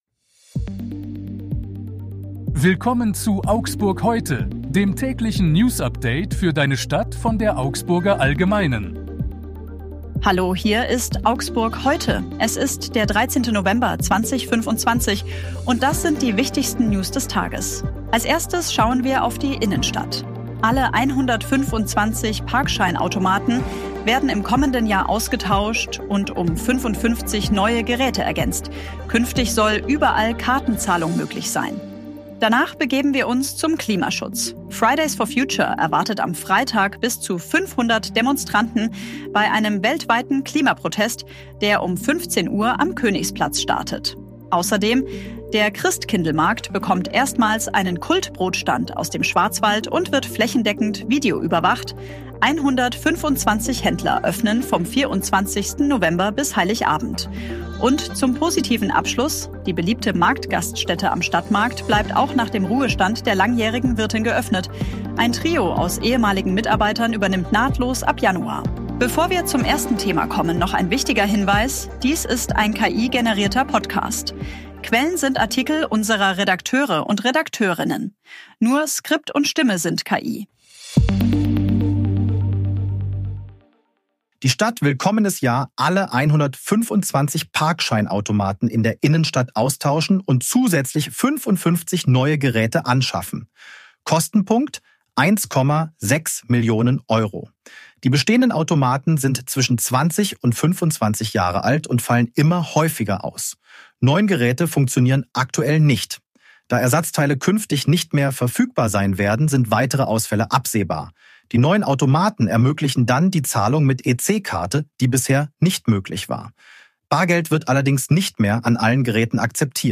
Hier ist das tägliche Newsupdate für deine Stadt.
Nur Skript und Stimme sind KI